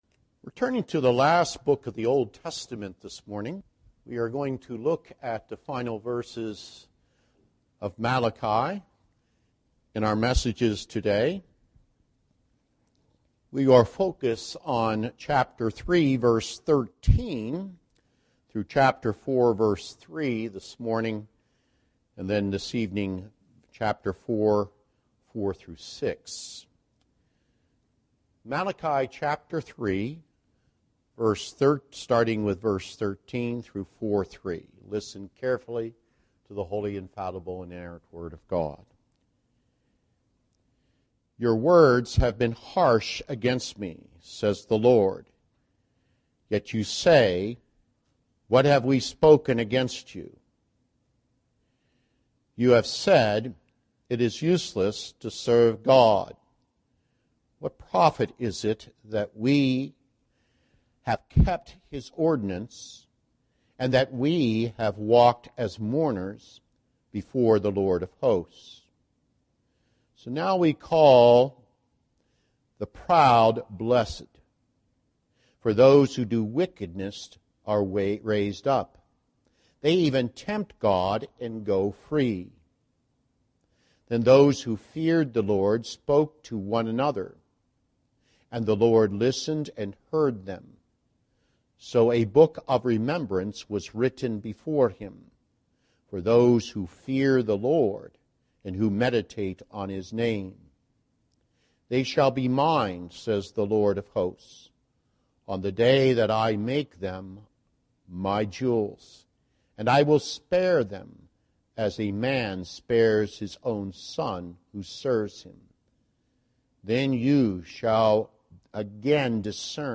Series: Guest Preachers Passage: Malachi 3:13 – 4:3 Service Type: Sunday Morning Service « The Trophy That Became a Terror Be Obedient